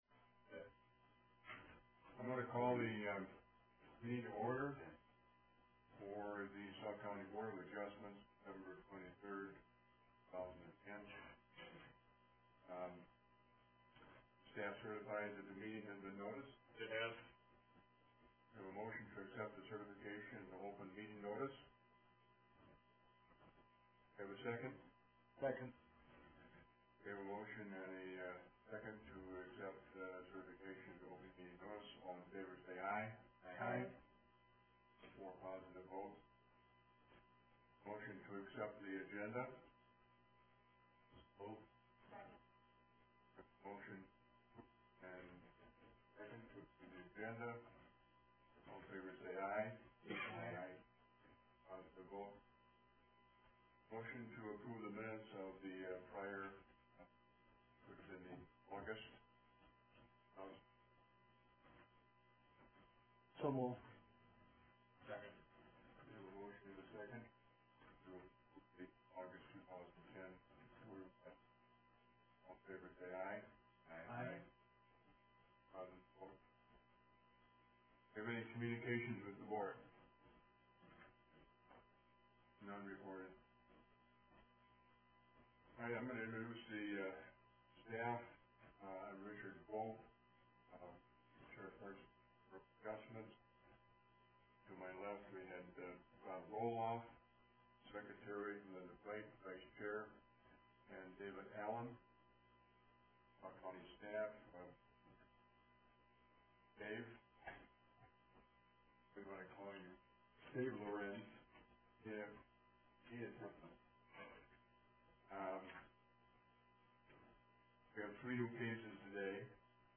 Board of Adjustment public hearing beginning at 9:00 a.m.